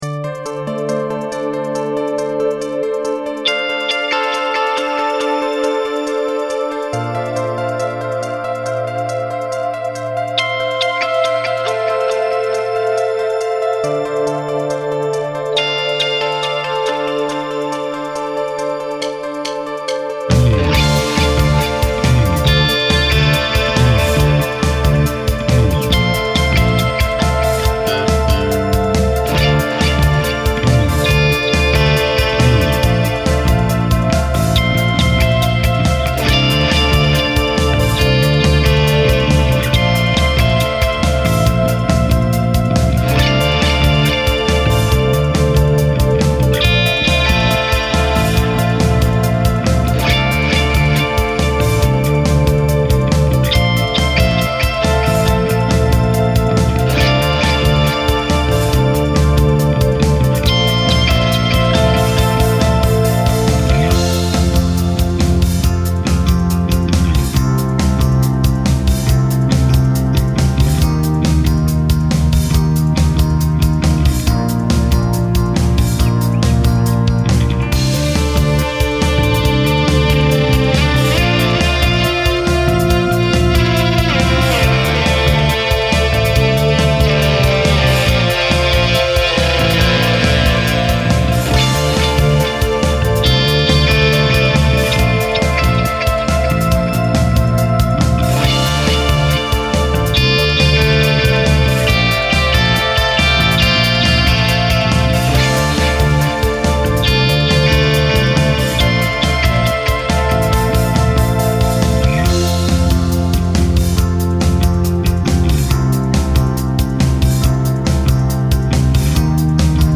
BPM : 139